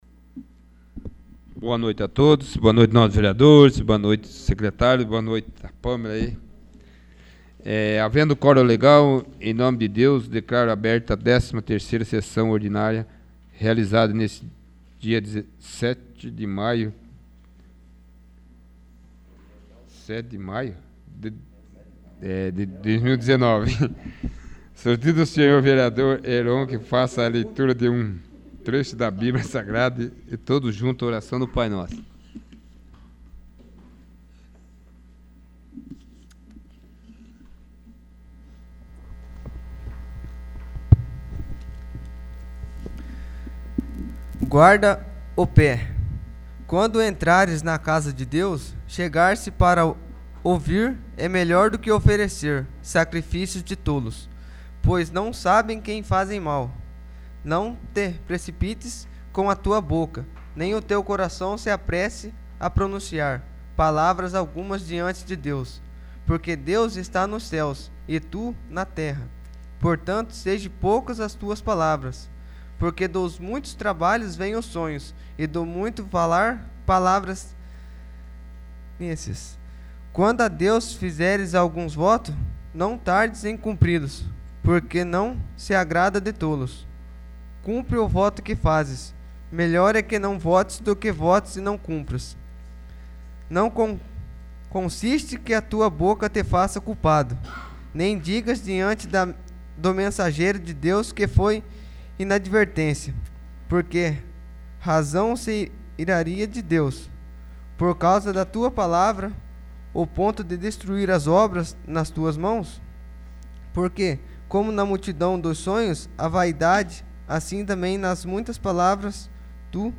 13º. Sessão Ordinária